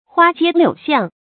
花街柳巷 注音： ㄏㄨㄚ ㄐㄧㄝ ㄌㄧㄨˇ ㄒㄧㄤˋ 讀音讀法： 意思解釋： 花、柳：舊指娼妓。